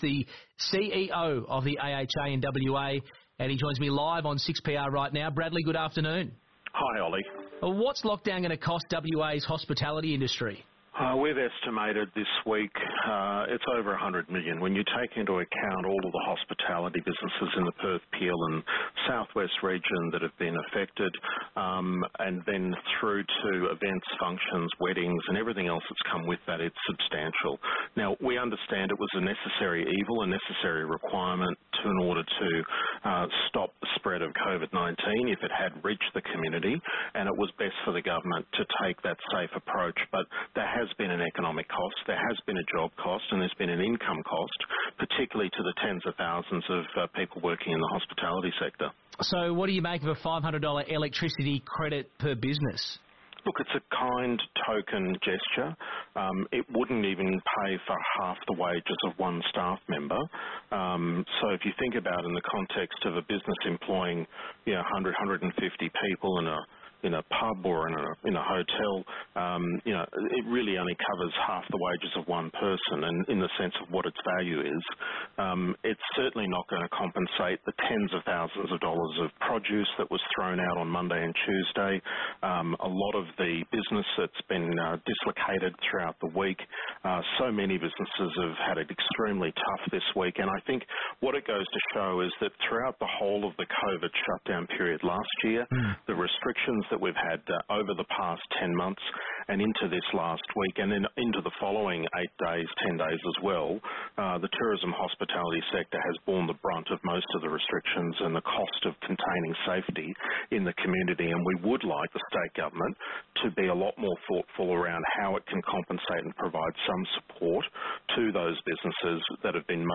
interview with 6PR – The lockdown cost to WA’s hospitality industry